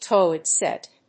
アクセントtóilet sèt